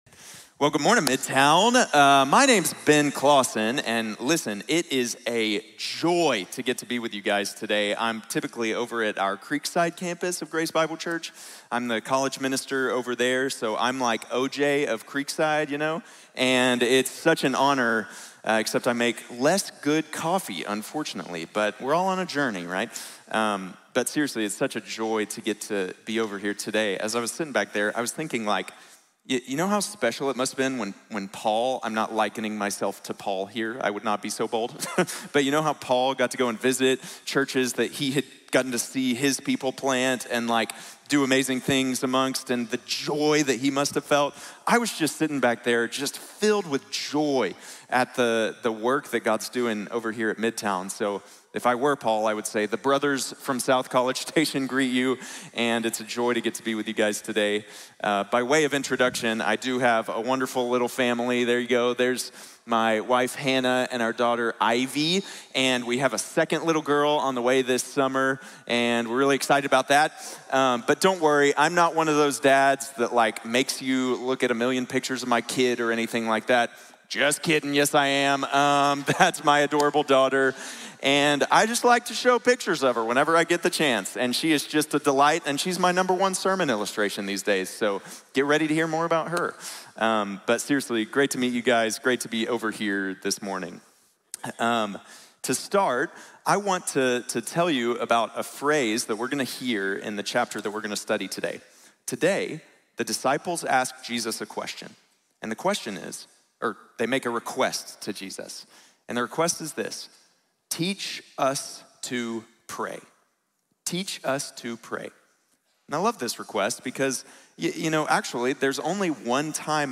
Enséñanos a Orar | Sermón | Iglesia Bíblica de la Gracia